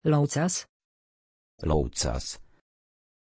Aussprache von Loucas
pronunciation-loucas-pl.mp3